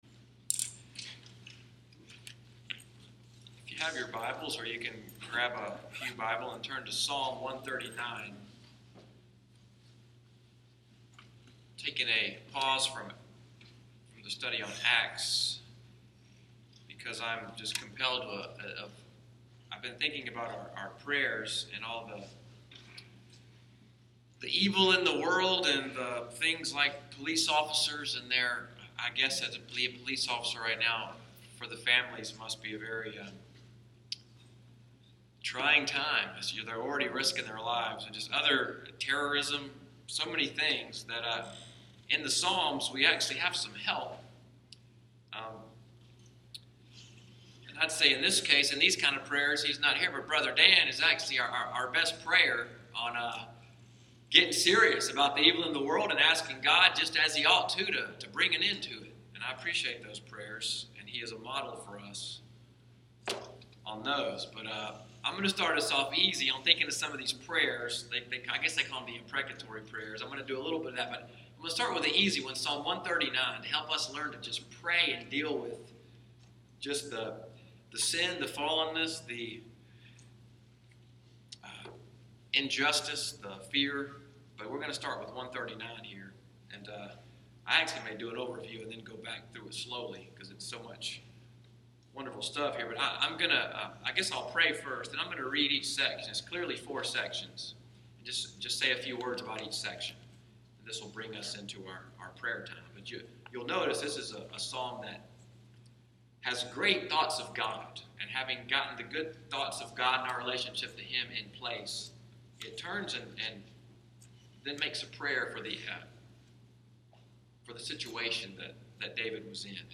Wednesday Evening Bible Study/Prayer Meeting at NCPC.
psalm_139_study.mp3